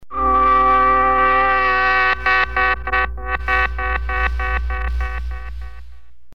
KTTS Tornado Warning alert every 5 minutes until the warned period expires
000-save-old-ktts-tornado-warning-tone.mp3